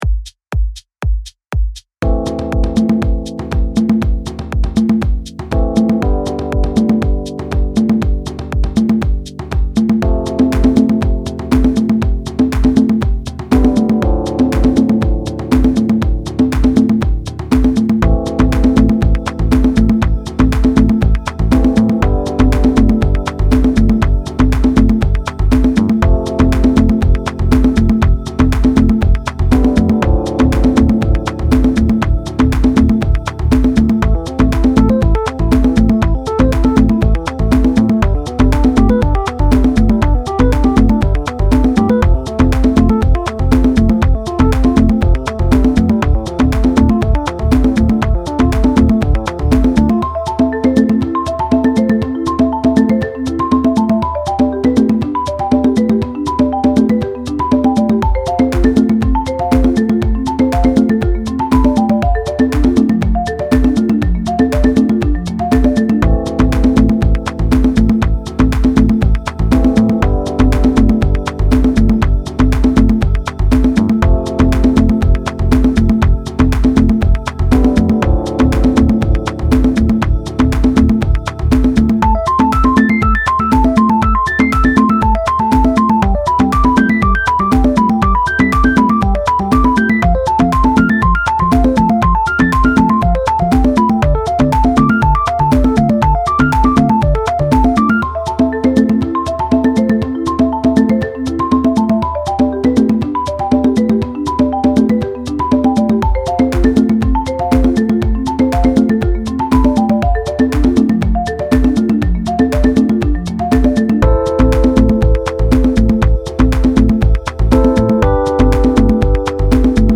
A Liberian rhythm made popular by the venerable Babatunde Olatunji.
high part audio (with shekeré)
Both parts played together with small percussion
These Dialogue Rhythms (88) are each made up of a high drum and a low drum pattern. As the high and low tones weave together and interact, melodic figures are created.